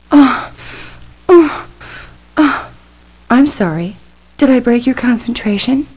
SPOKEN STUFF